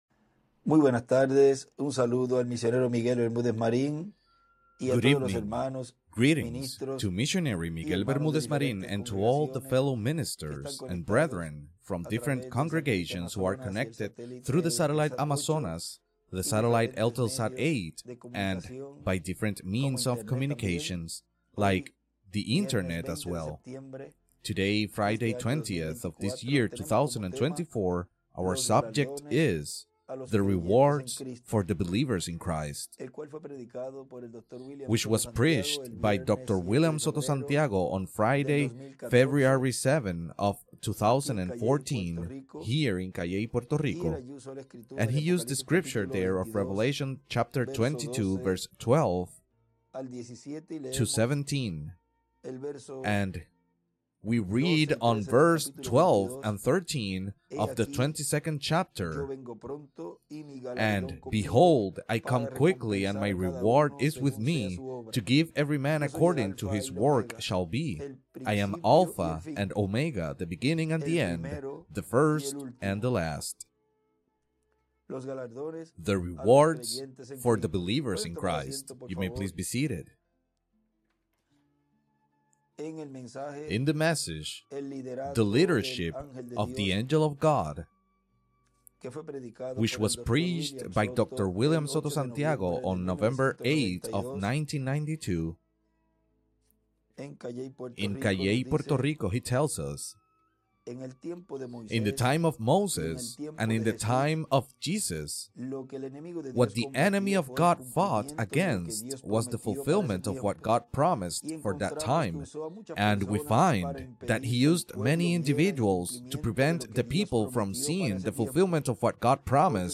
2024 Activity Descargas Audio Libro BIBLE STUDY #448 FRIDAY